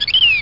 Blackbird Sound Effect
Download a high-quality blackbird sound effect.
blackbird-2.mp3